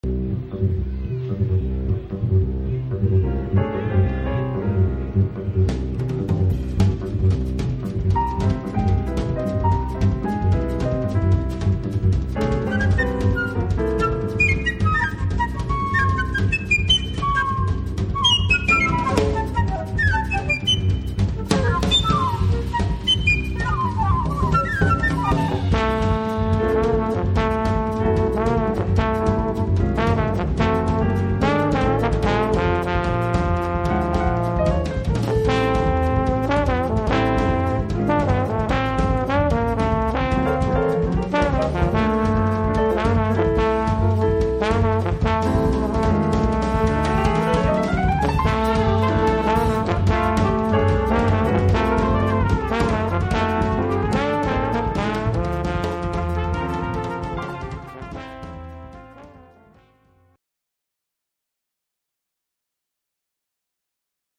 Recorded at the Chicago Jazz Festival,